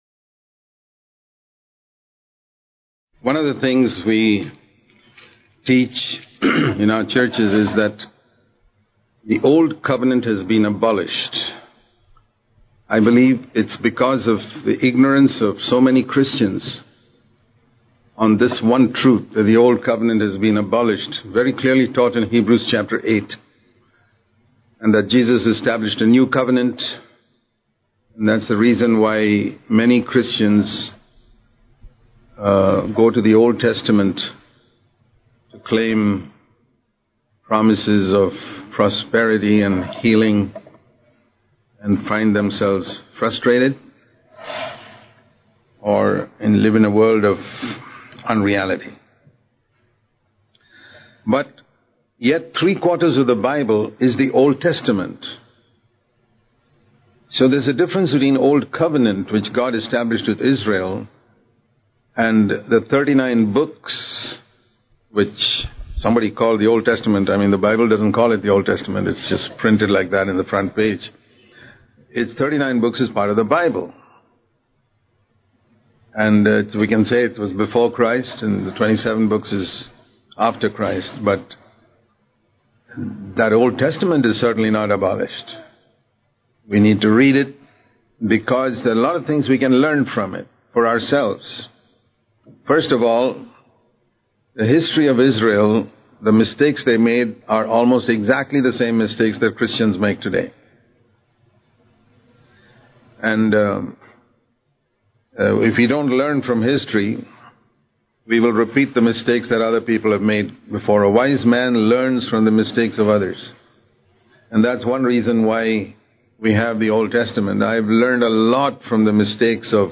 at NCCF, California, USA